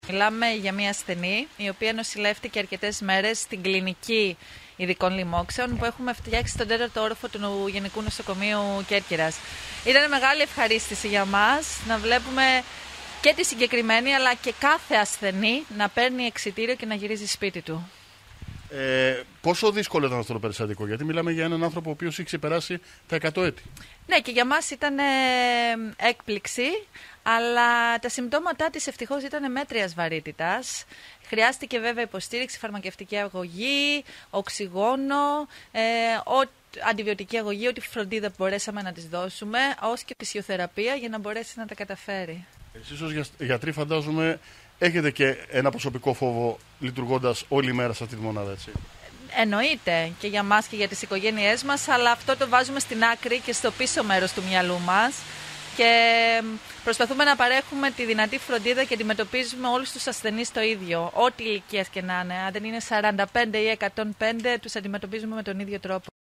Περιφερειακοί σταθμοί ΚΕΡΚΥΡΑ